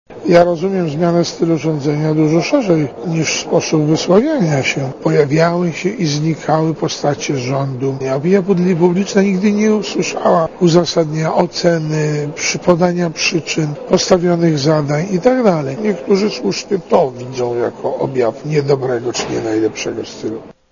– odpowiada Kaczmarkowi Józef Oleksy.